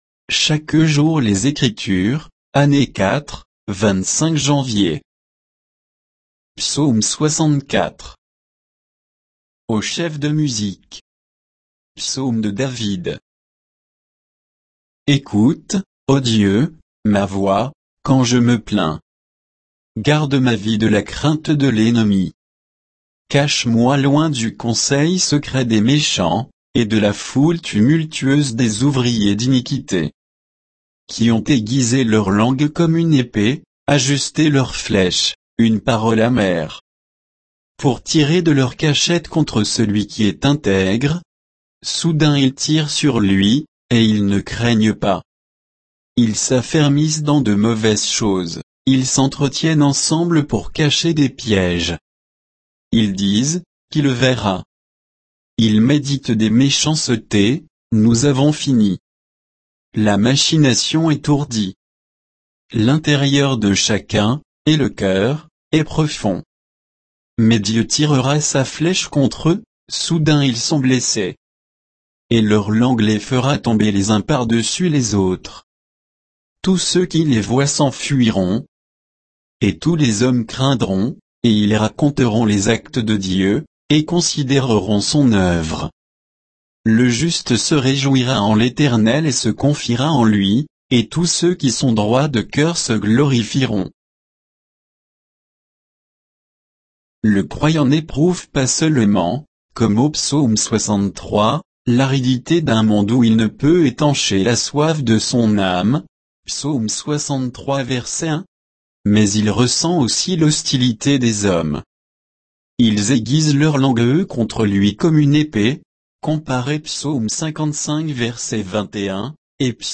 Méditation quoditienne de Chaque jour les Écritures sur Psaume 64